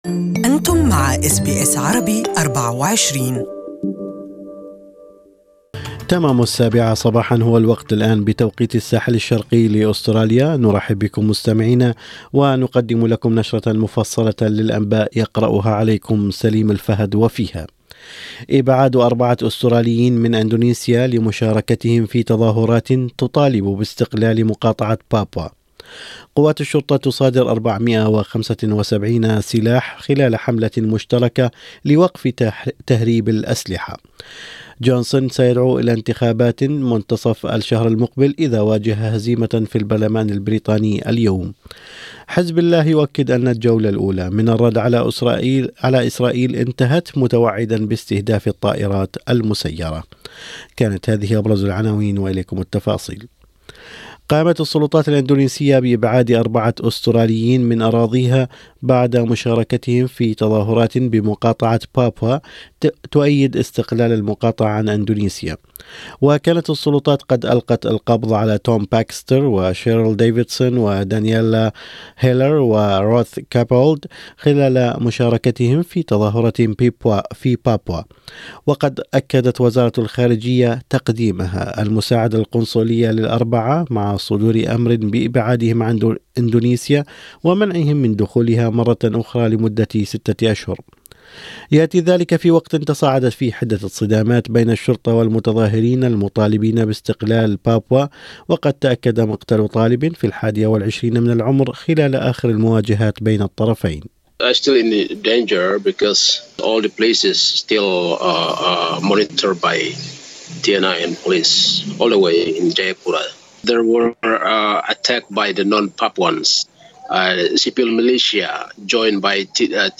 Morning News: Hundreds of firearms seized across Australia